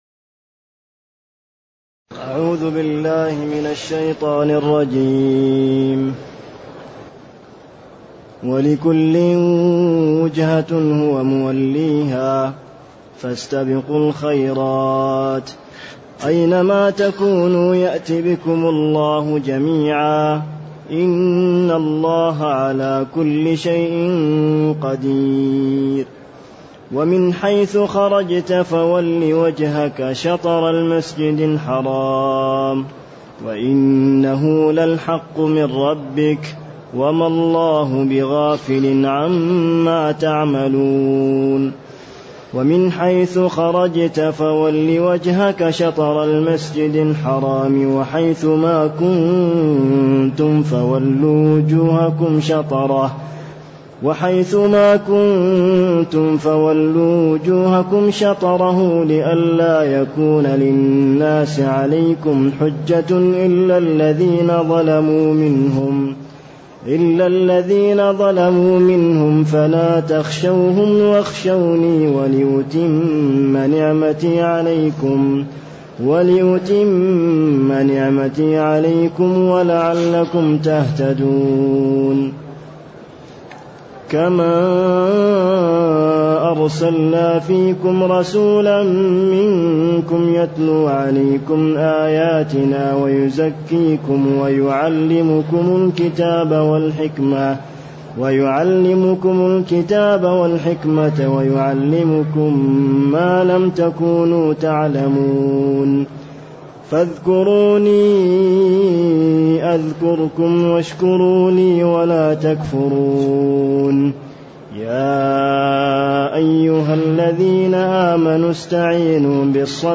تاريخ النشر ٢ رجب ١٤٢٨ هـ المكان: المسجد النبوي الشيخ